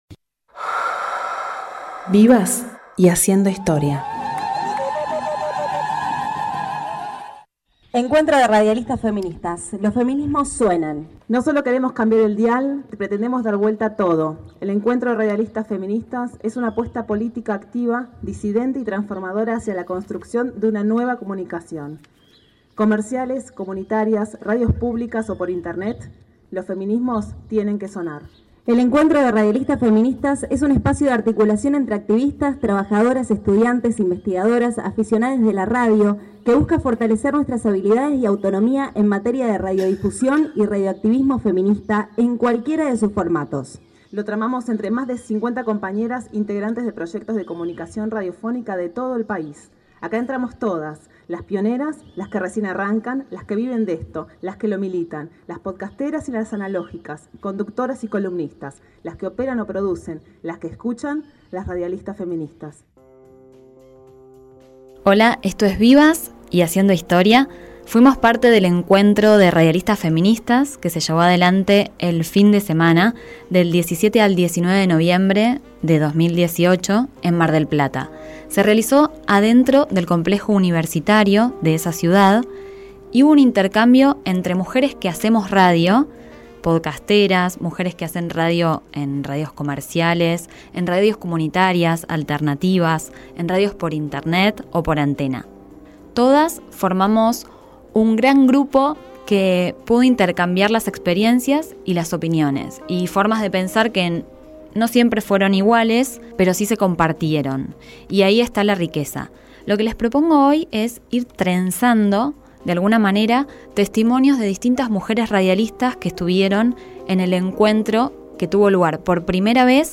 es un programa de entrevistas e historias de vida
se transmite en vivo por Radio Hache y cuenta con el apoyo de Mujeres Urbanas.